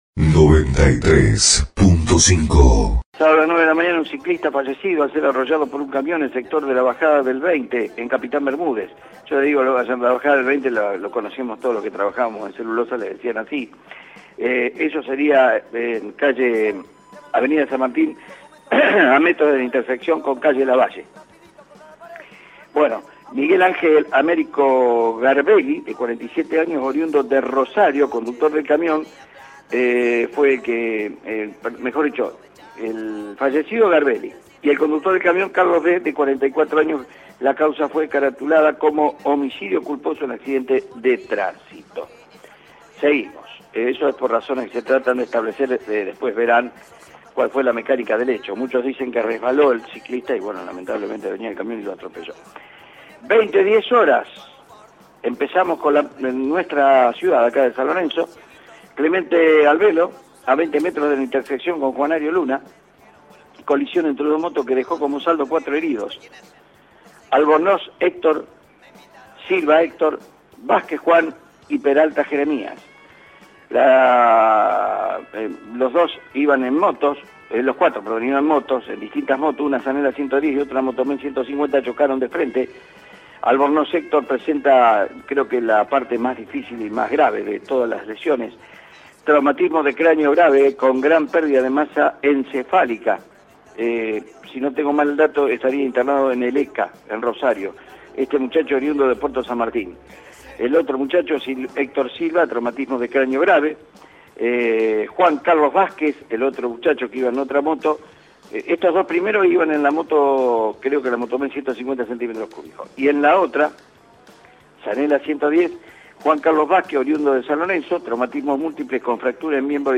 Informe de hechos policiales de las últimas horas